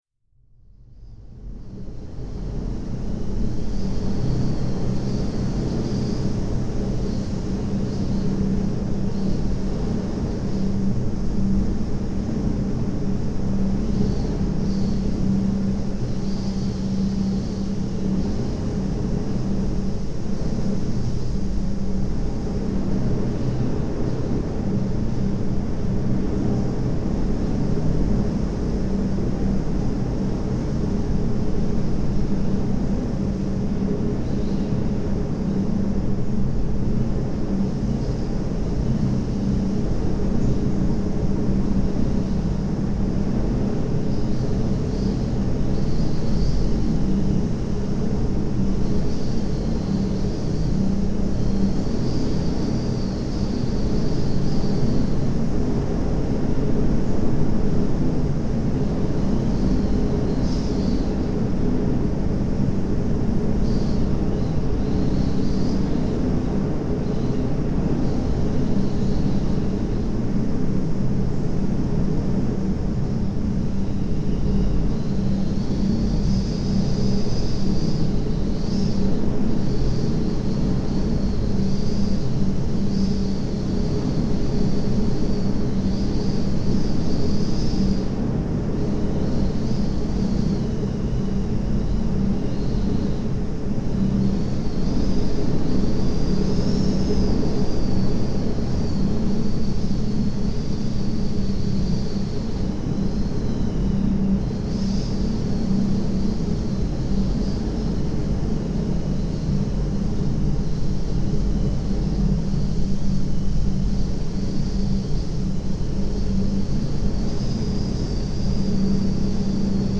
The recordings of the resonating lighthouse and radio masts and wires were made during summer gales on Skomvær Island in 2022 and 2023.
Skomvaer-lighthouse.mp3